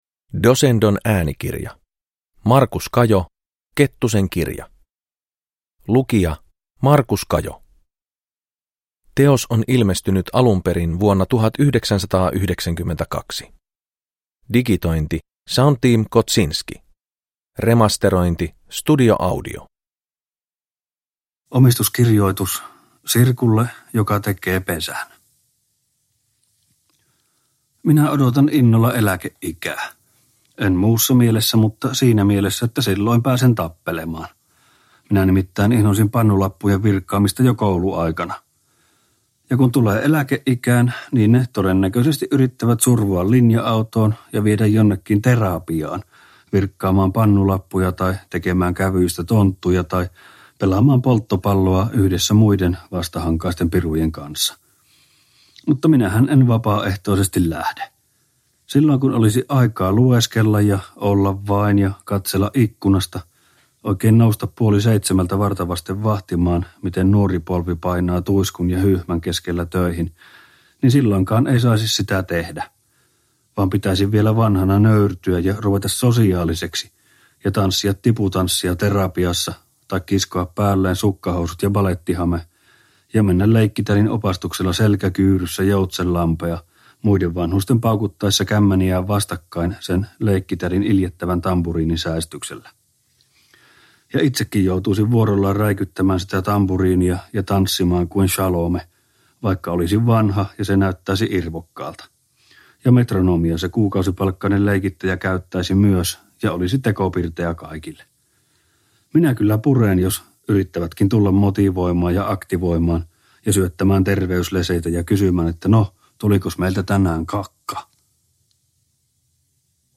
Uppläsare: Markus Kajo